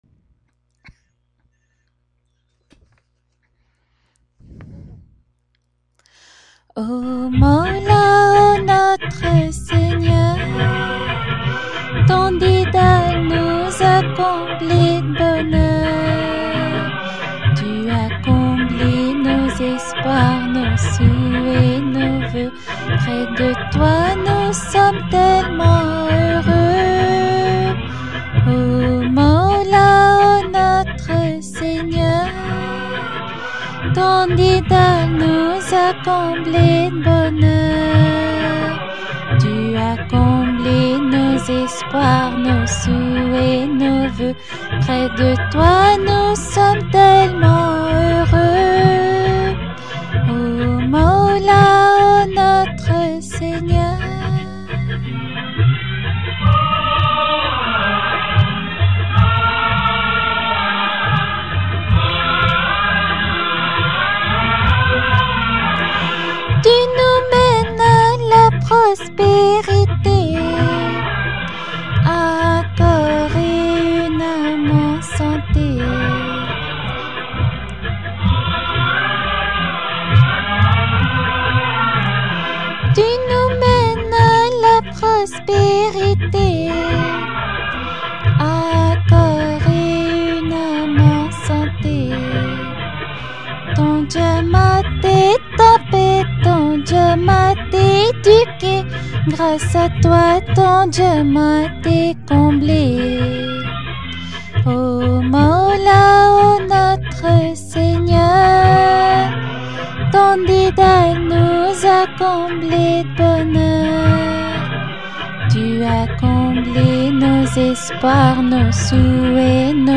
MP3: La Musique / The Tune